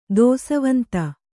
♪ dōsavanta